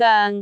speech
syllable
pronunciation
goeng6.wav